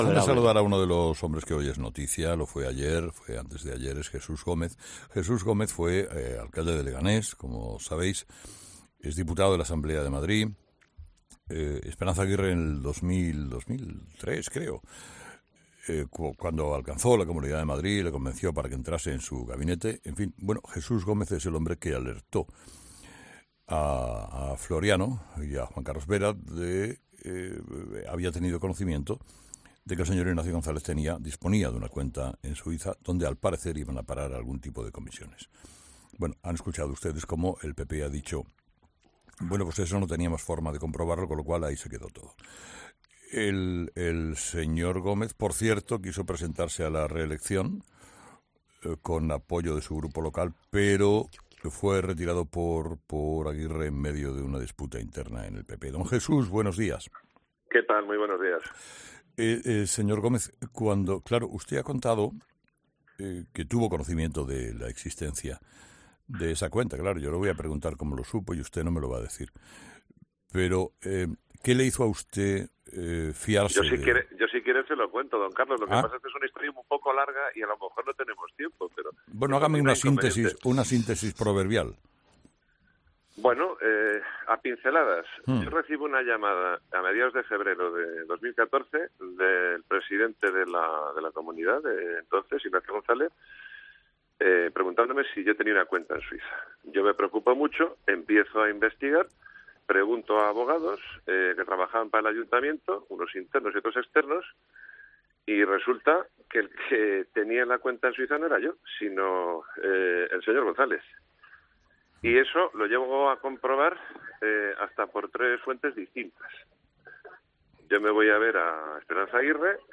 El diputado del PP en la Asamblea de Madrid Jesús Gómez